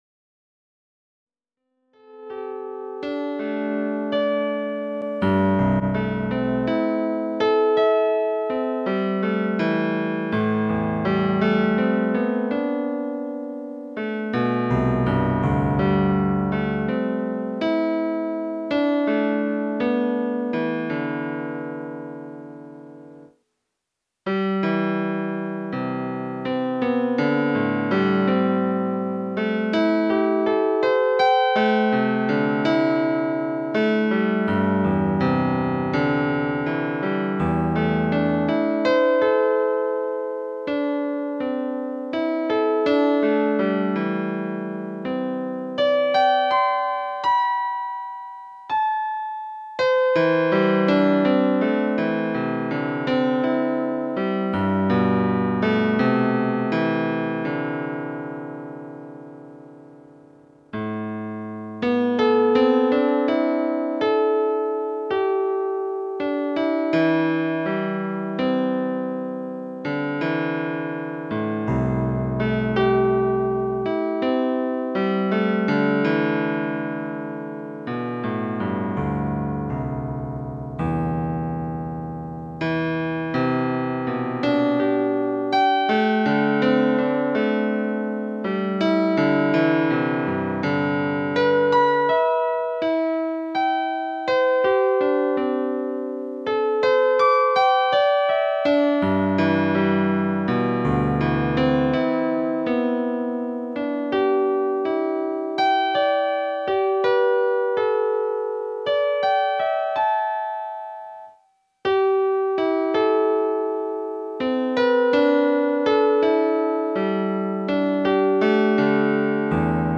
Vous pouvez maintenant écouter deux versions de la musique du Séquoia !!